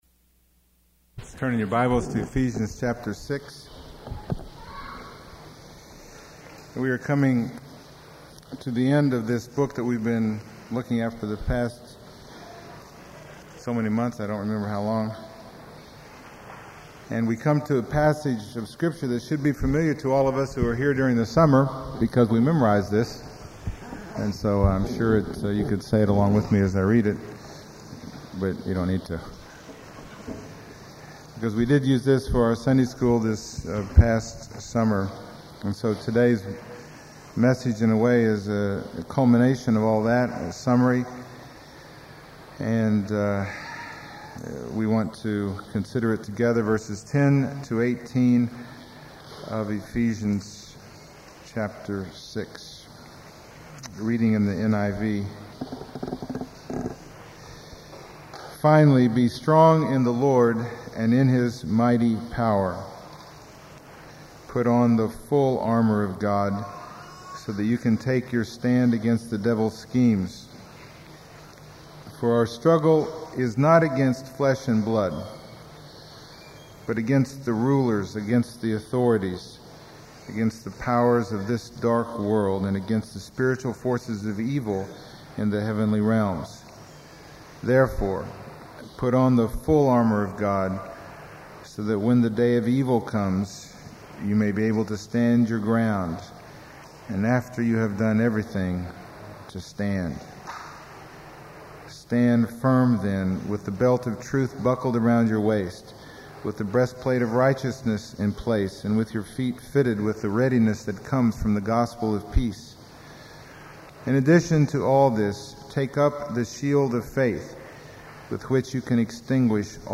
Ephesians Passage: Ephesians 6:10-18 Service Type: Sunday Morning %todo_render% « Be Filled